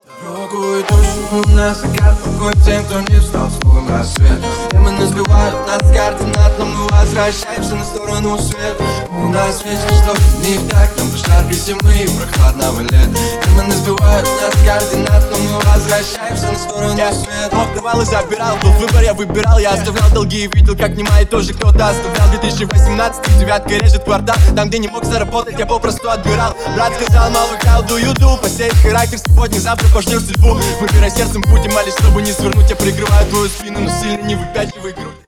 Ремикс # Рэп и Хип Хоп
клубные